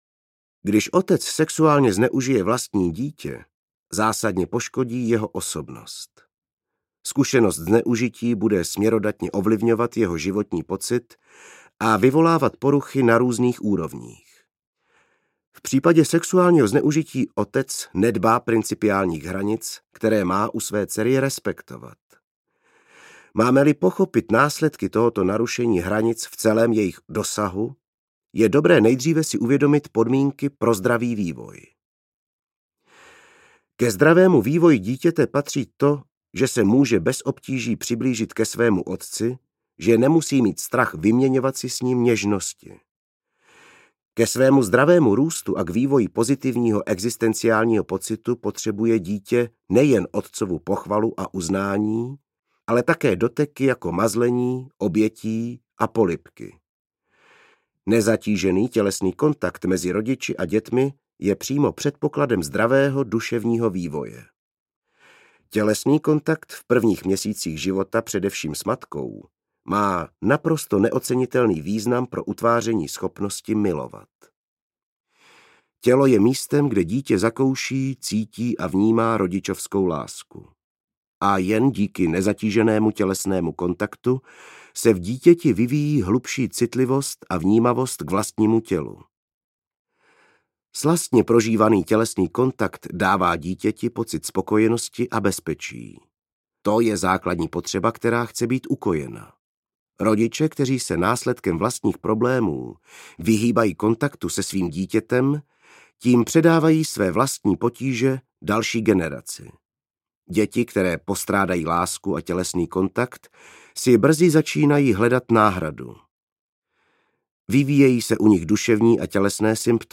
Ukázka z knihy
Vyrobilo studio Soundguru.
zneuziti-audiokniha